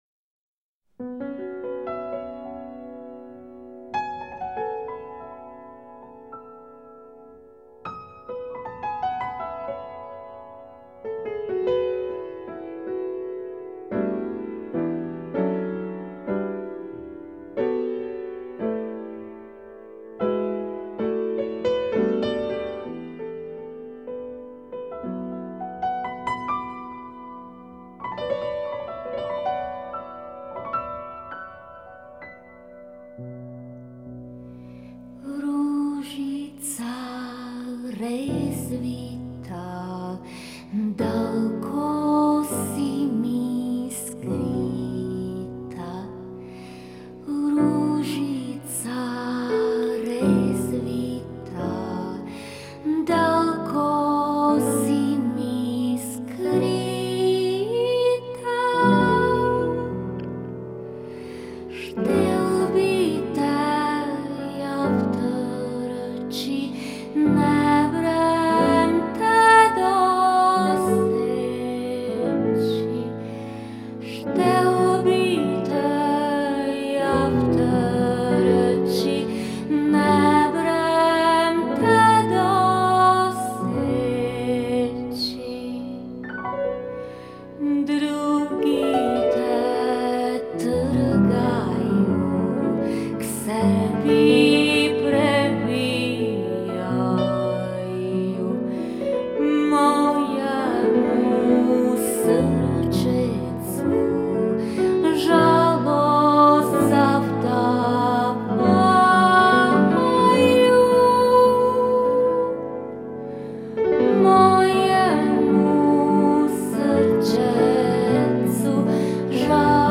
glas i gitara
glasovir i udaraljke
cimbale